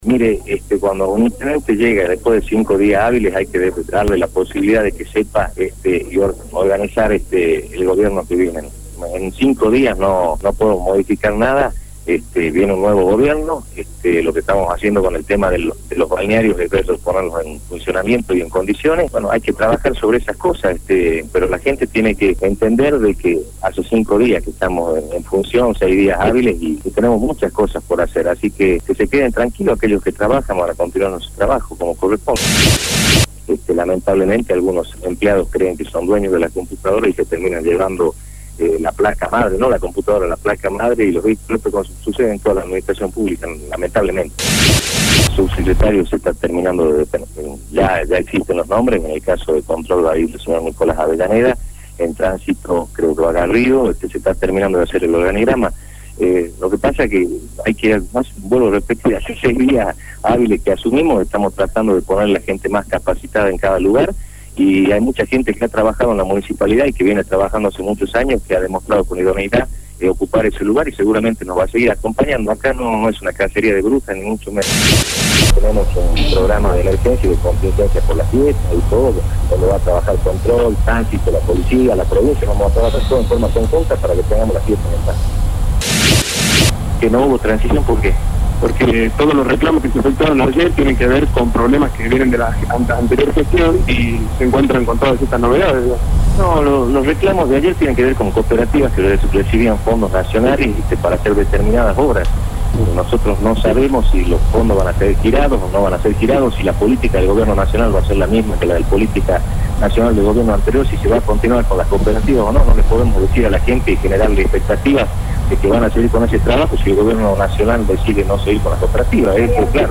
El nuevo Intendente de la Ciudad de Salta se refirió sobre su gestión.